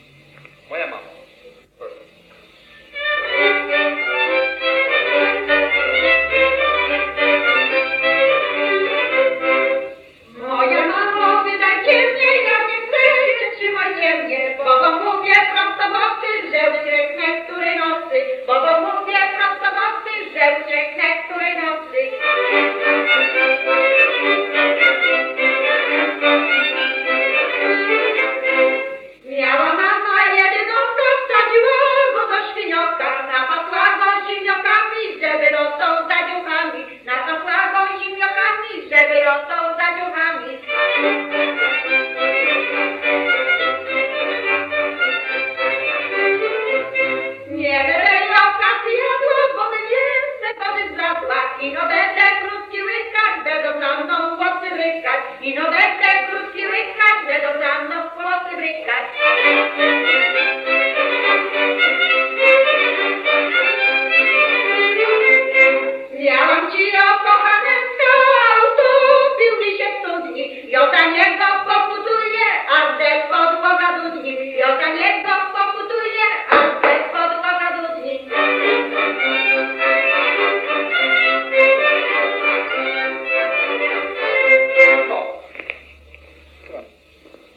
Moja mamo, wydajcie mnie – Żeńska Kapela Ludowa Zagłębianki
Nagrania archiwalne (I skład kapeli)